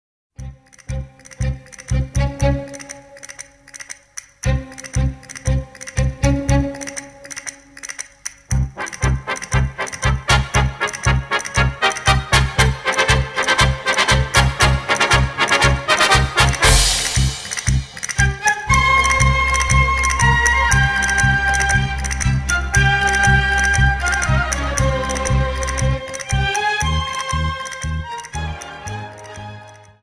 Dance: Paso Doble 59